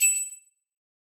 Metallic Ting
Alert Ding Metallic Ring Ting sound effect free sound royalty free Sound Effects